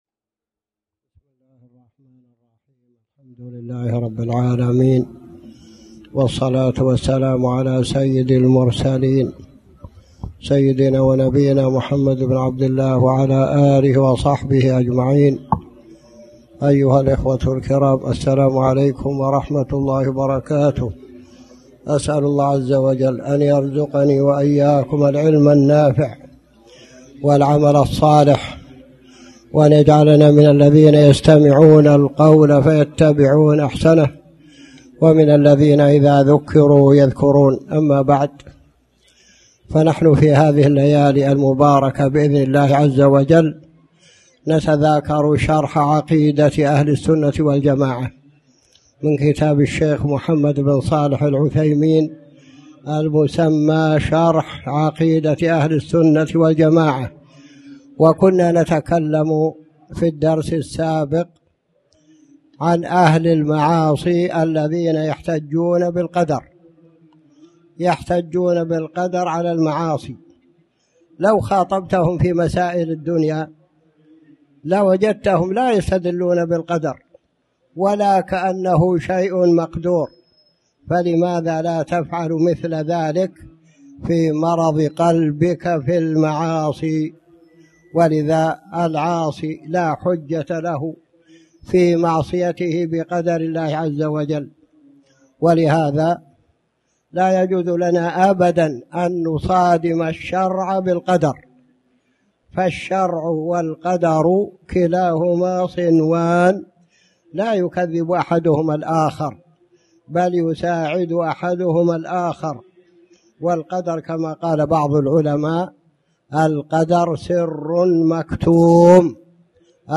تاريخ النشر ٨ ربيع الأول ١٤٣٩ هـ المكان: المسجد الحرام الشيخ